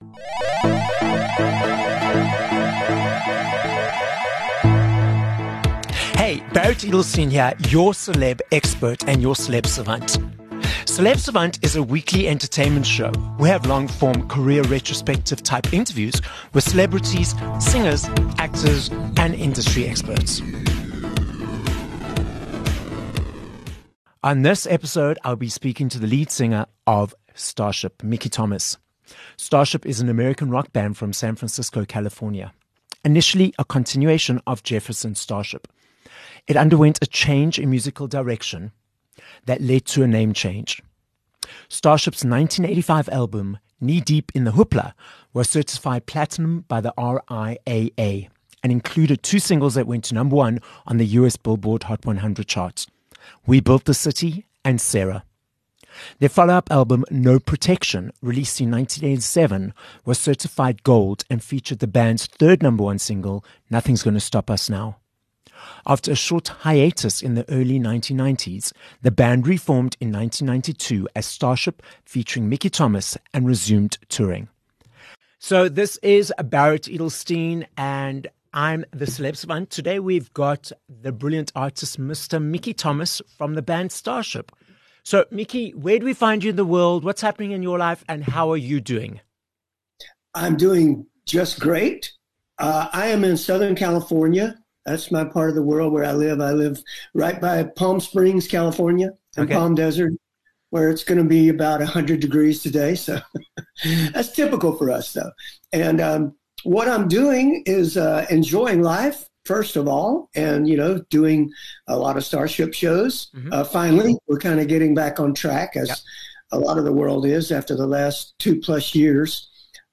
16 Jul Interview with Mickey Thomas from Starship